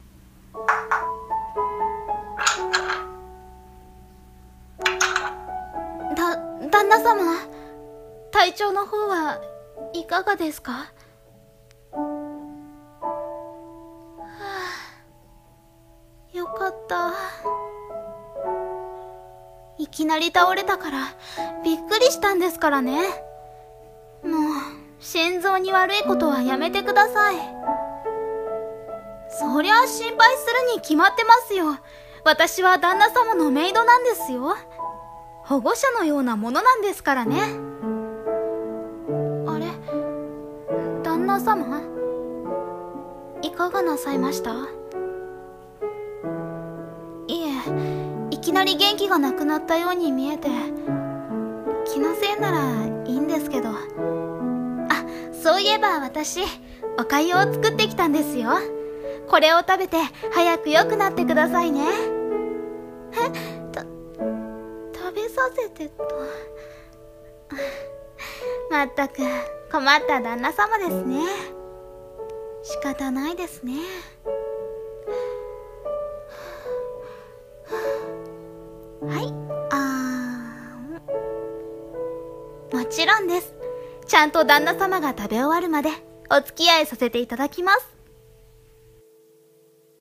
[一人声劇] 看病執事orメイド